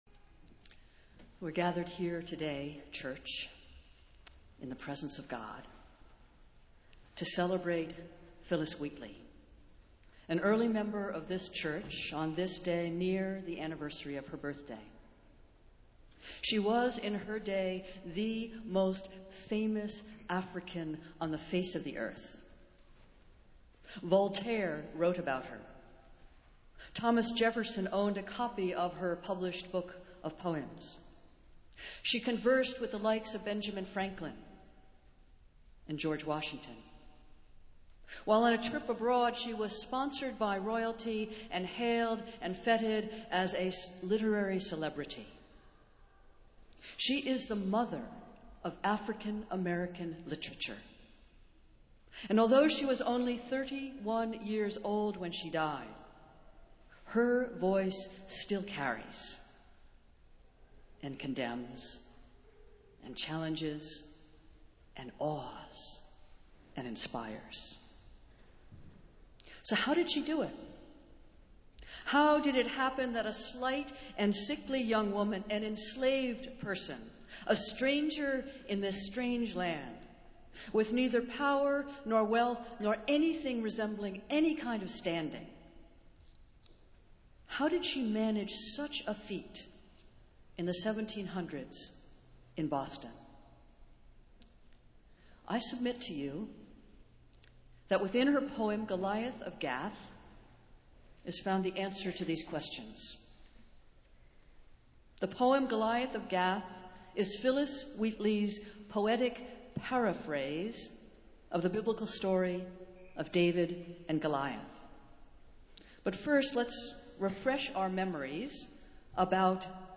Festival Worship - Phillis Wheatley Sunday